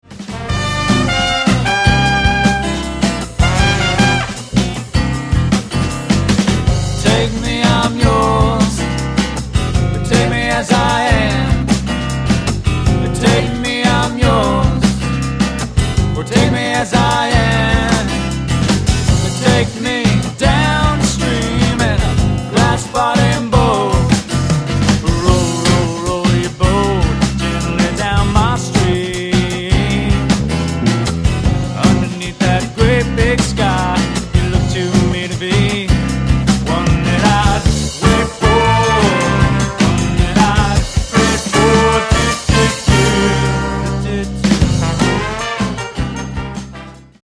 Recorded at Newmarket Studios